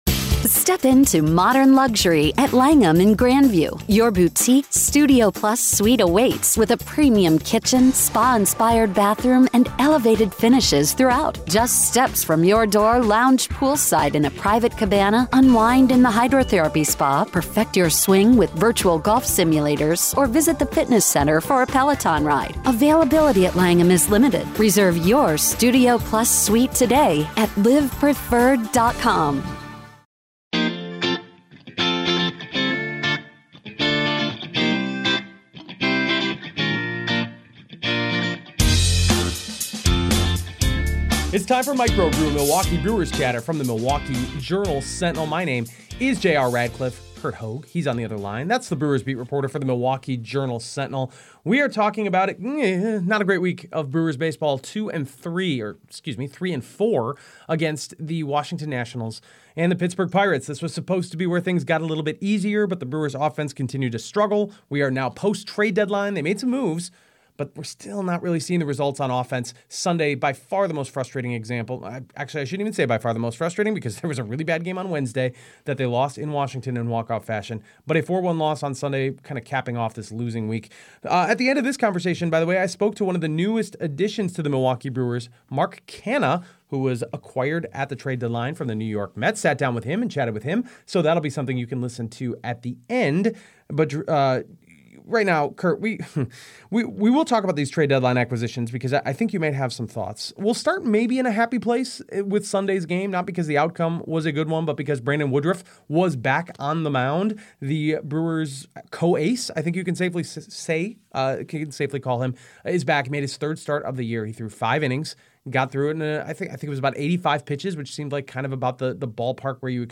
Brandon Woodruff is back, but the offense has stayed the same, plus a conversation with Mark Canha (08.07.2023)